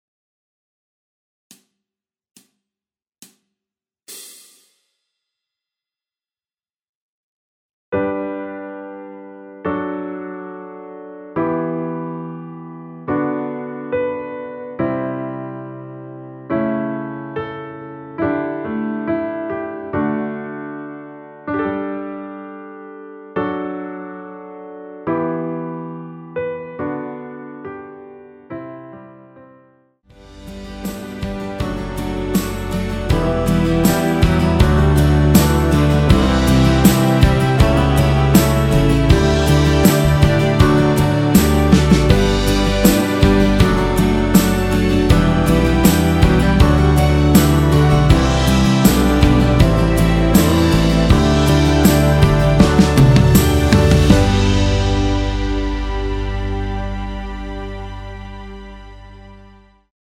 노래가 바로 시작하는곡이라 카운트 넣어 놓았으며
그리고 엔딩이 너무 길고 페이드 아웃이라 라랄라 반복 2번으로 하고 엔딩을 만들었습니다.
앞부분30초, 뒷부분30초씩 편집해서 올려 드리고 있습니다.